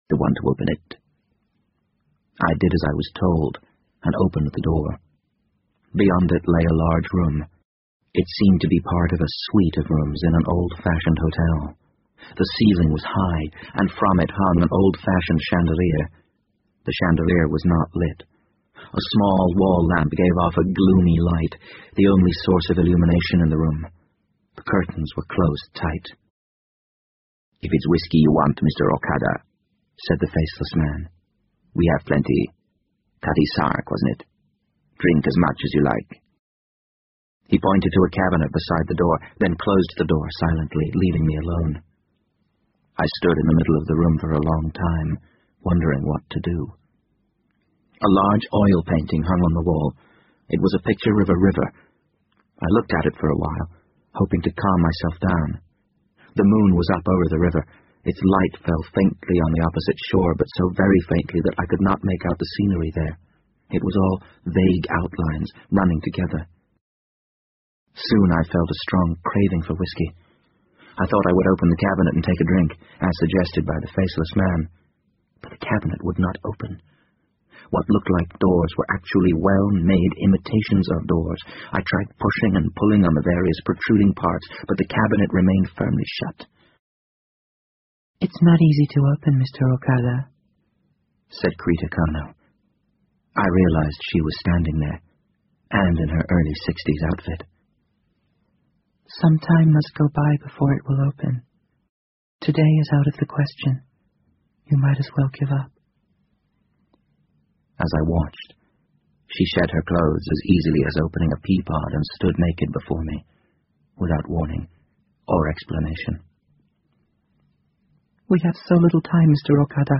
BBC英文广播剧在线听 The Wind Up Bird 51 听力文件下载—在线英语听力室